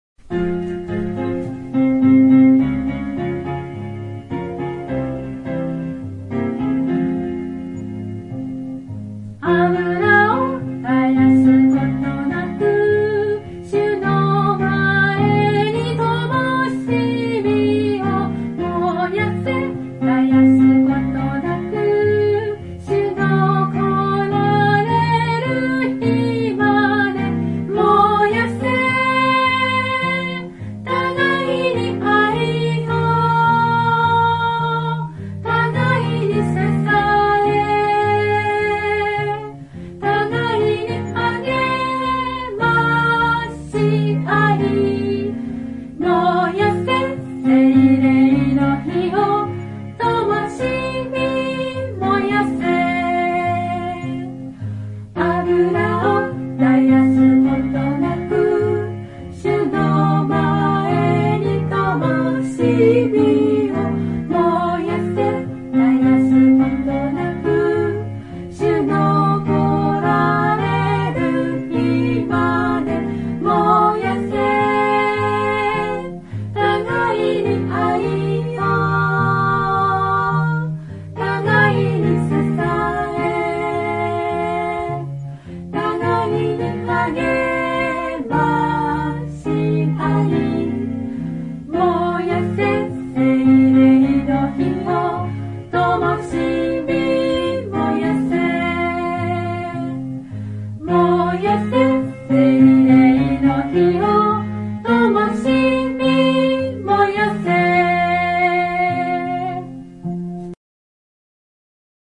（徳島聖書キリスト集会集会員）